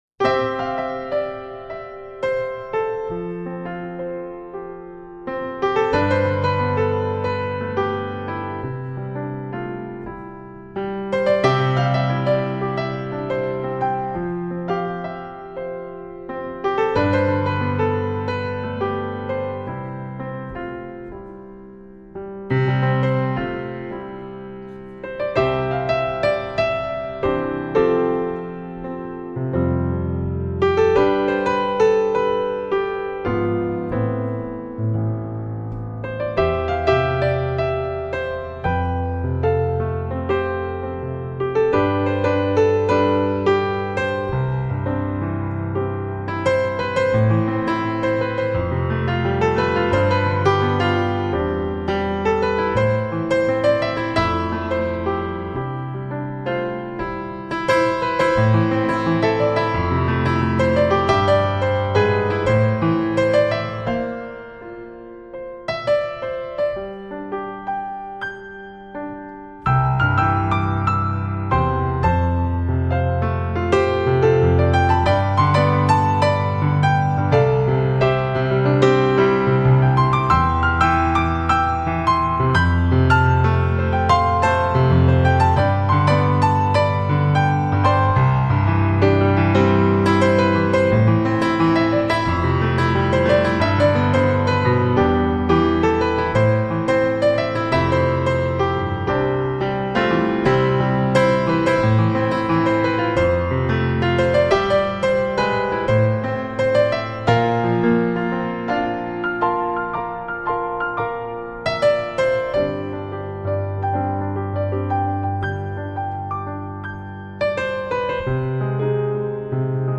钢琴的音质素来明净如水。